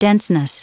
Prononciation du mot denseness en anglais (fichier audio)
Prononciation du mot : denseness